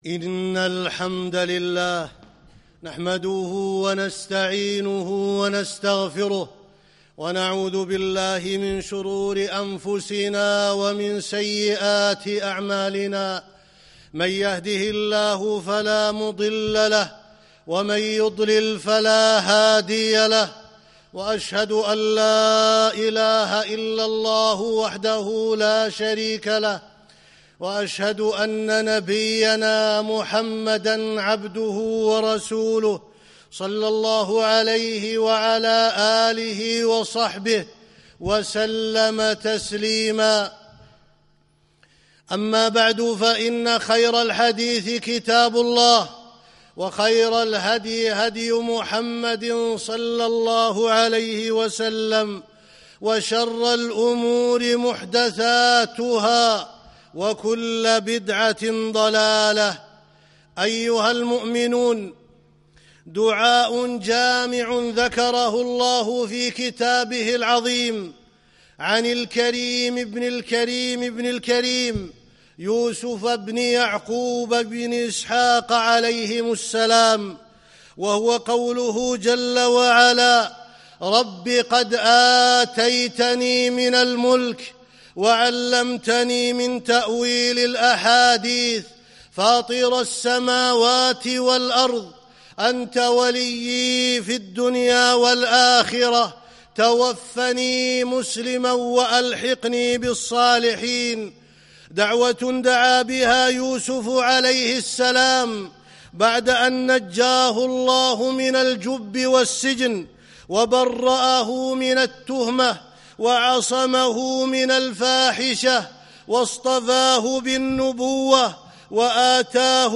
دعاء يوسف عليه السلام - خطبة